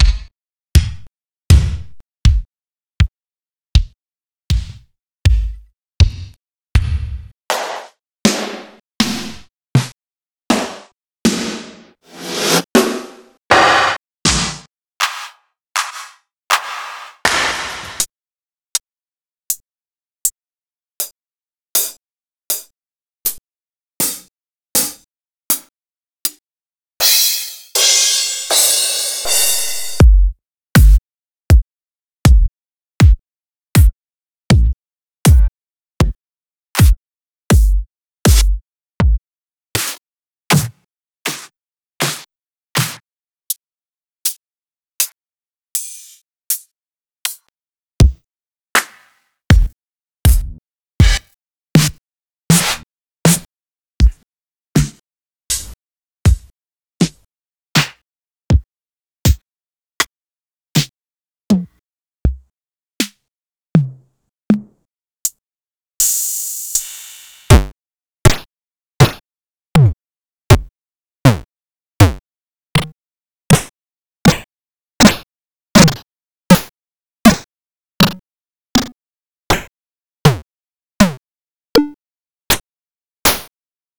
Index of /m8-backup/M8/Samples/Drums
Drums16M.wav